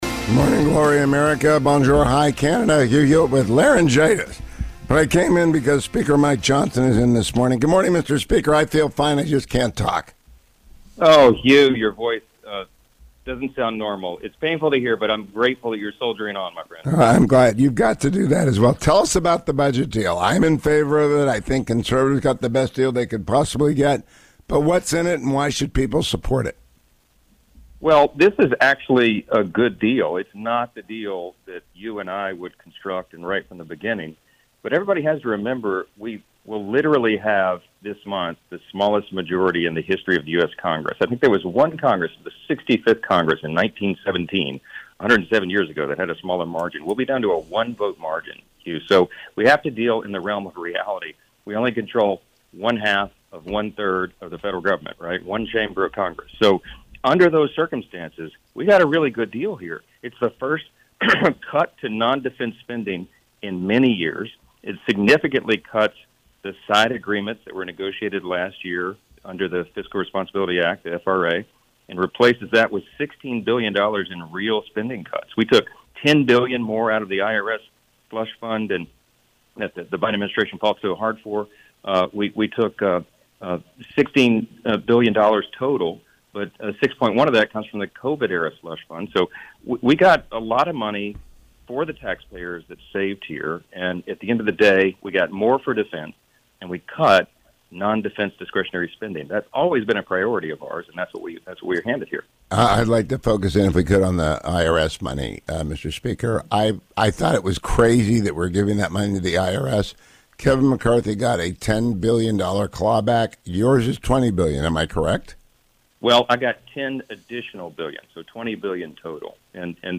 Speaker of the House Mike Johnson (R-LA) joined me this morning: